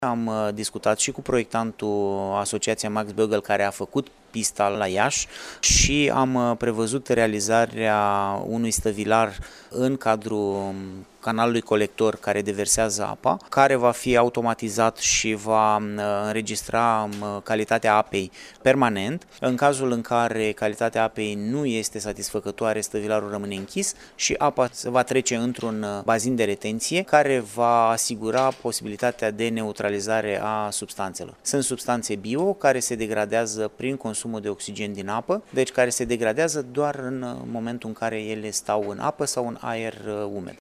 UPDATE 10.04.2019, ora 14:07  – Conducerea Aeroportului Iaşi a recunoscut, astăzi, într-o conferinţă de presă, că poluarea de pe lacul Ciric III Veneţia a fost cauzată de agentul dejivrant de la pistă folosit în urmă cu aproape 6 săptămâni.